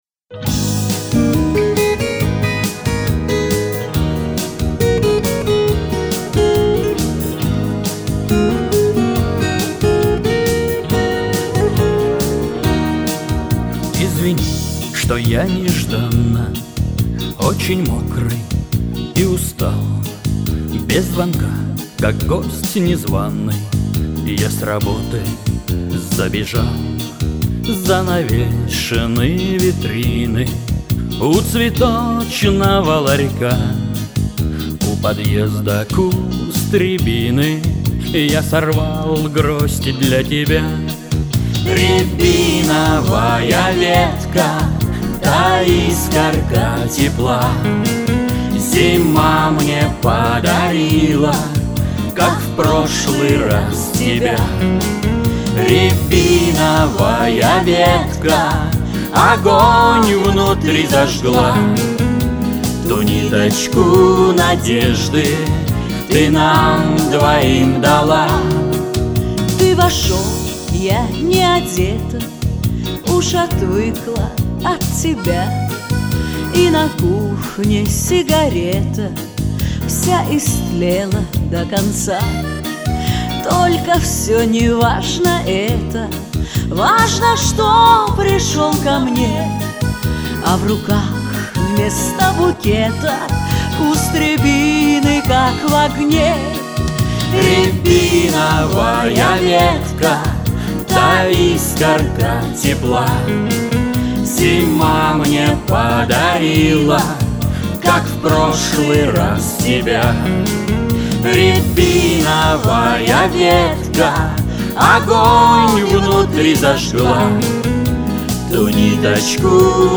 ну это точно лирическая............